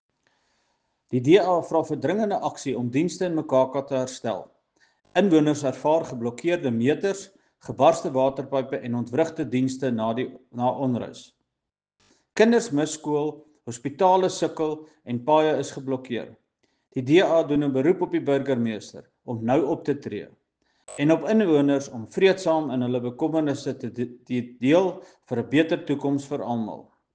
Afrikaans soundbites by Cllr Johan Spaski Geldenhuis and